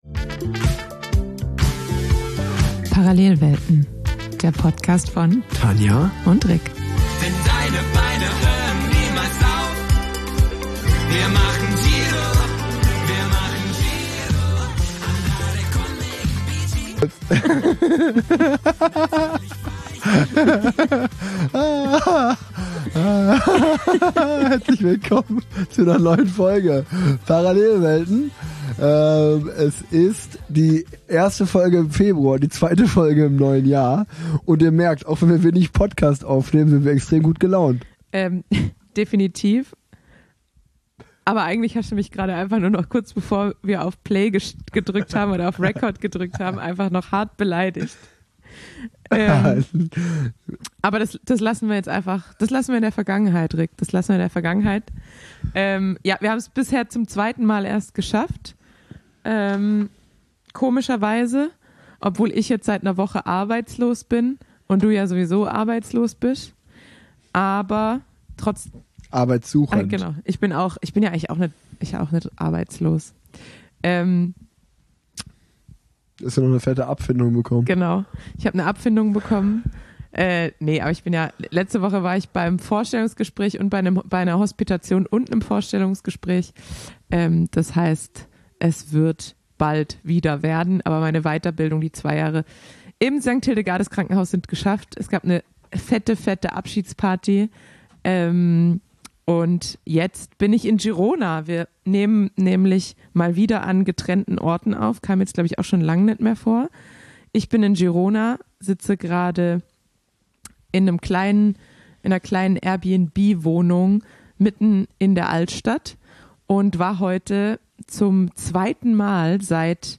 Interview